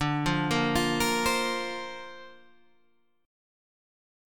Dm7#5 chord